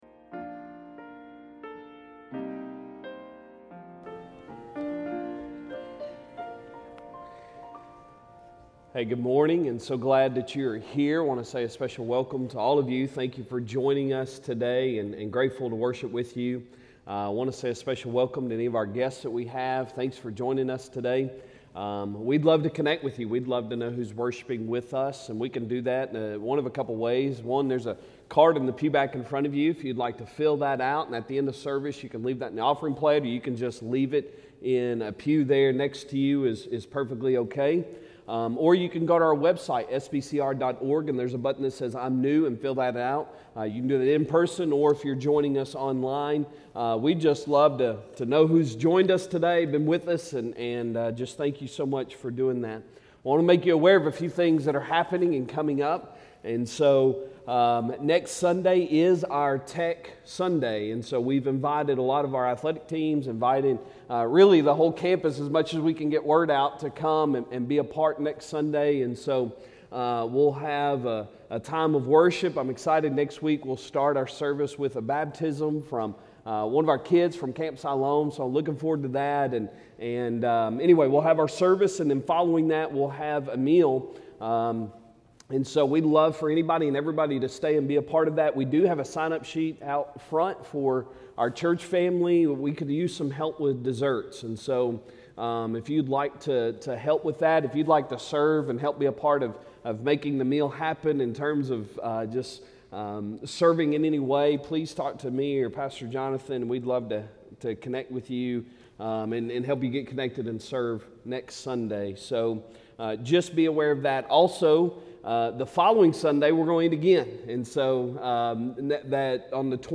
Sunday Sermon August 13, 2023